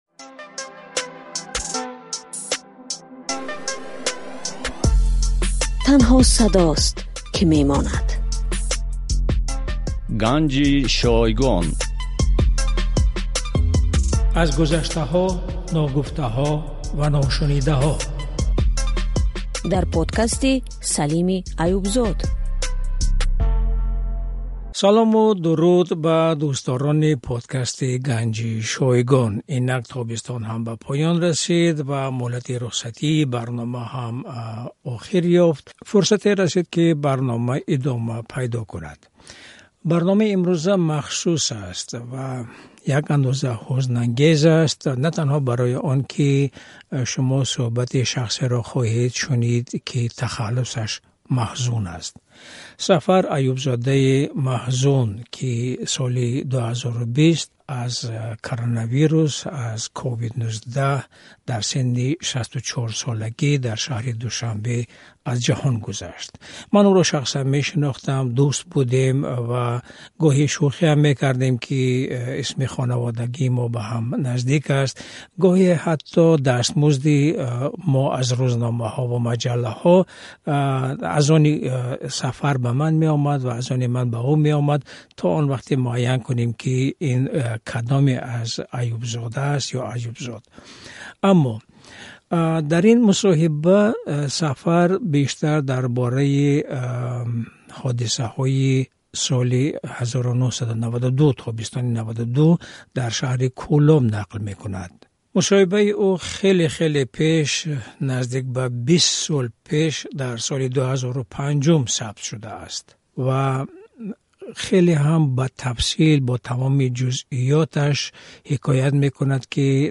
Гуфтугӯйи